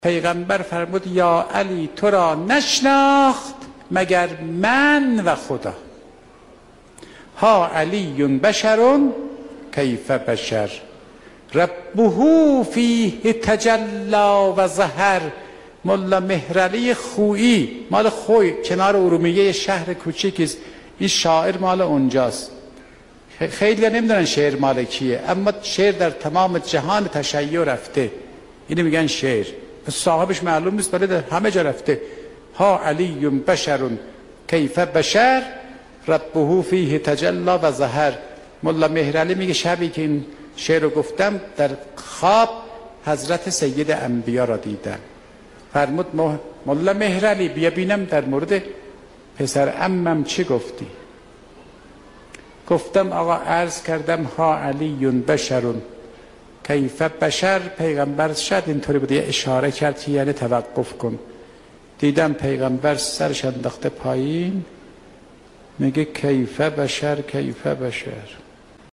در یکی از سخنرانی‌هایشان ضمن بیان این حدیث از پیغمبر اکرم(ص